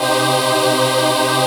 DM PAD4-04.wav